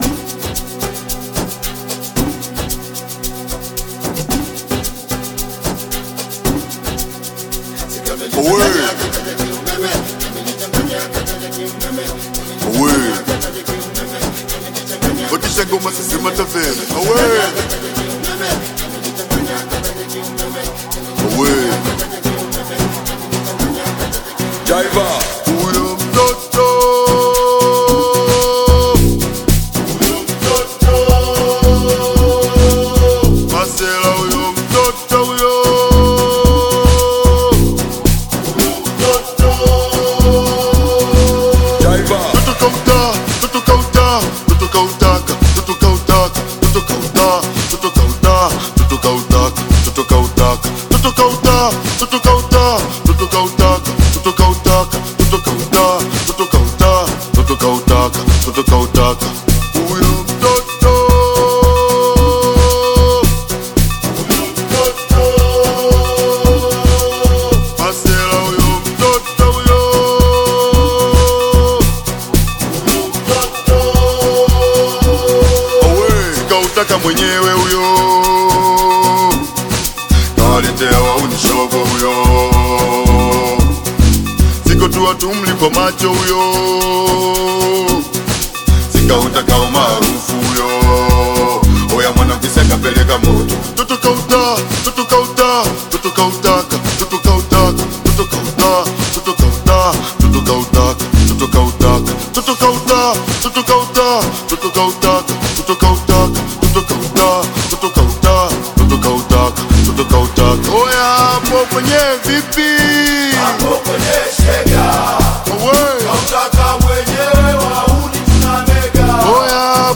With its fusion of Afrobeat and contemporary sounds